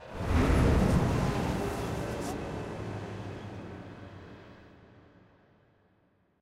Horror8.ogg